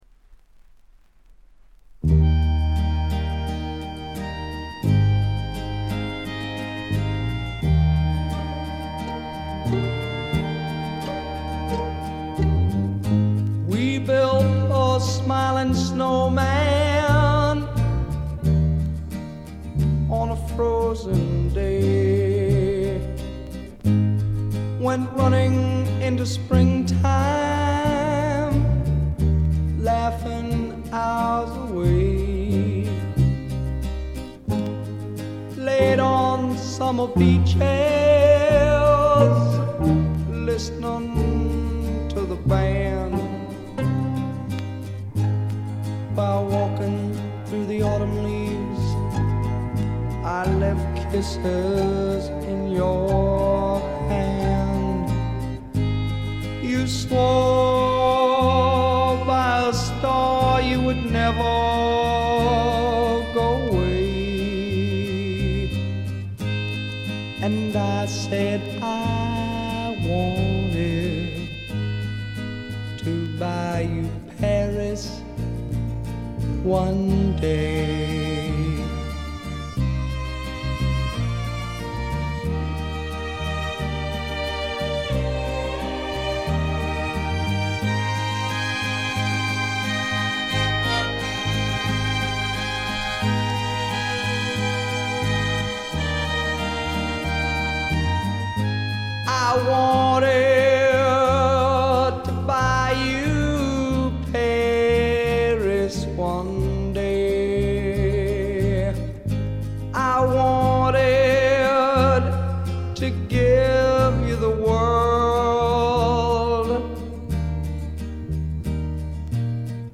ほとんどノイズ感無し。
胸を打つメランコリックでロンサムなバラードがとても良いです。
試聴曲は現品からの取り込み音源です。
Vocals, Guitar